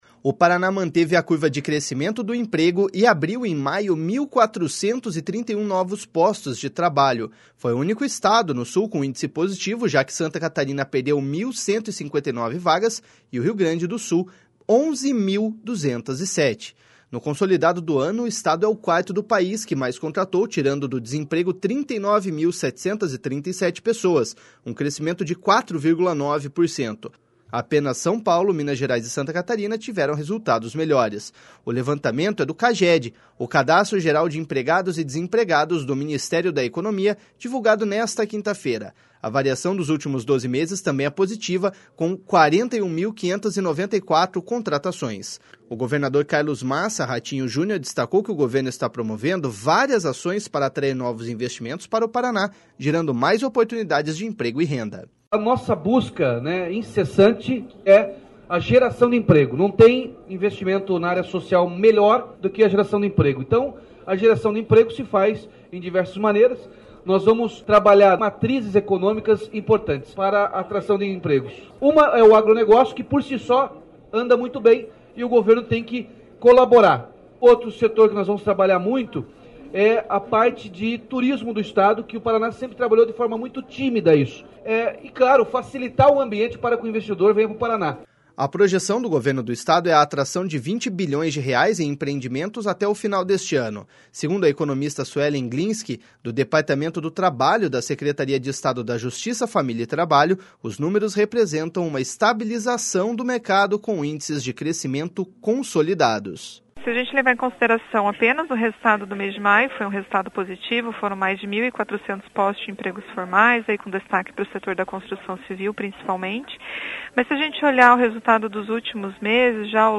O governador Carlos Massa Ratinho Junior destacou que o governo está promovendo várias ações para atrair novos investimentos para o Paraná, gerando mais oportunidades de emprego e renda.// SONORA RATINHO JUNIOR.//